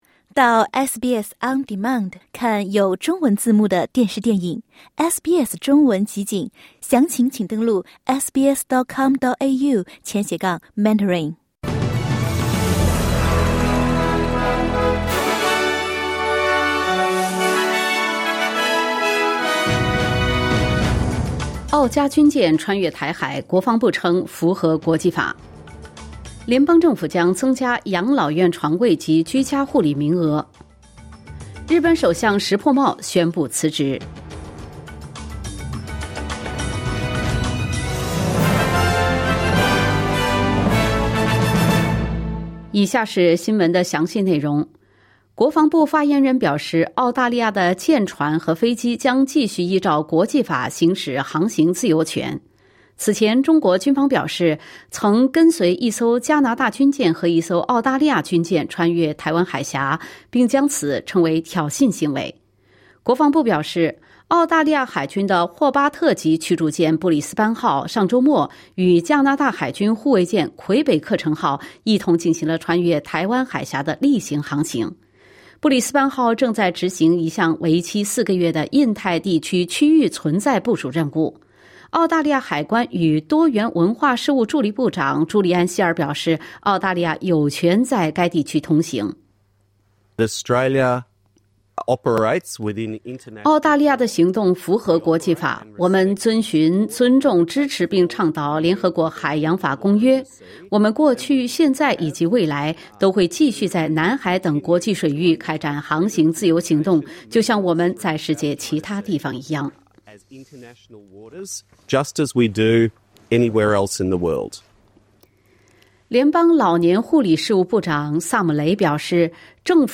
SBS早新闻（2025年9月8日）